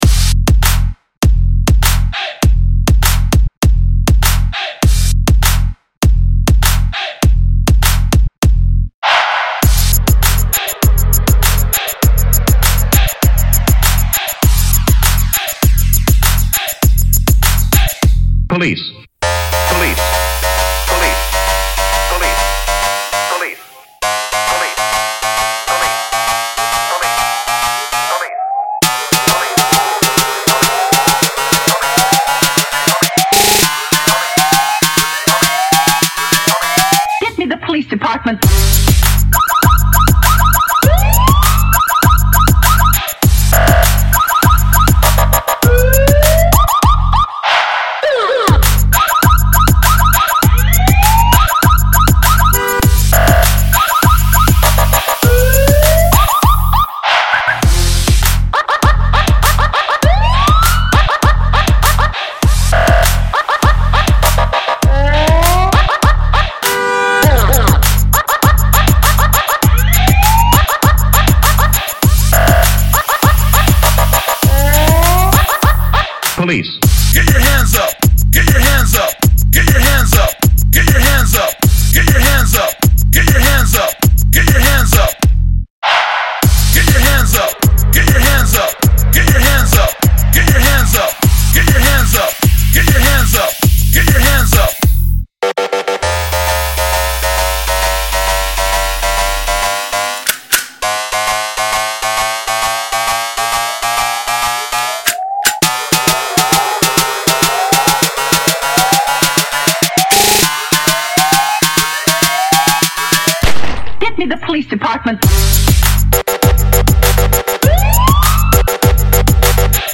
ریمیکس بیس دار آهنگ پلیسی شوتی خفن قوی بی کلام شوتی سواران